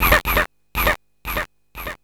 SFX61   01-R.wav